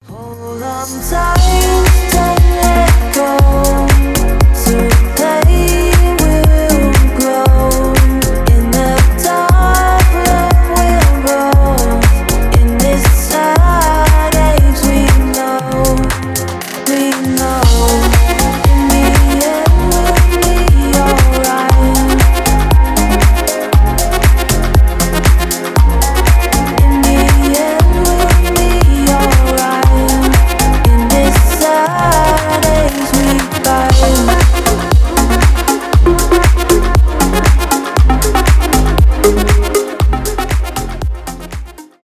deep house , dance pop
танцевальные